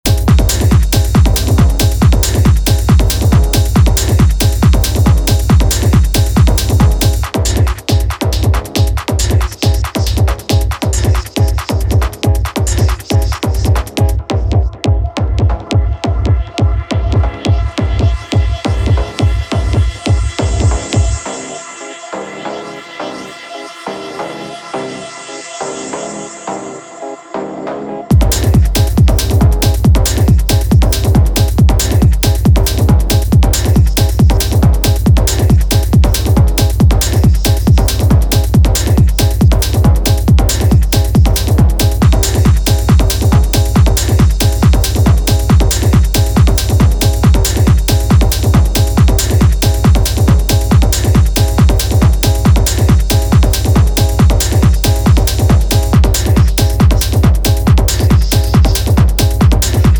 nicely groovy